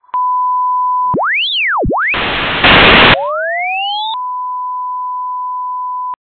Stream #0:0: Audio: aac (LC), 7350 Hz, stereo, fltp, 57 kb/s